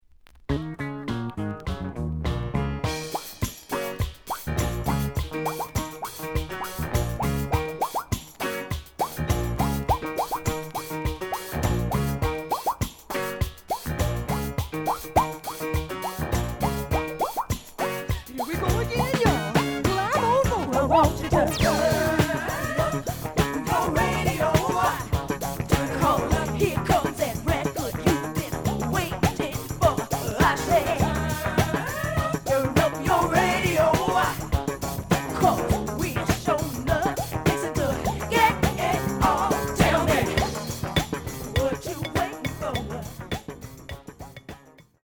The audio sample is recorded from the actual item.
●Genre: Funk, 70's Funk
Edge warp. But doesn't affect playing. Plays good.)